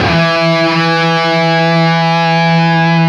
LEAD E 2 CUT.wav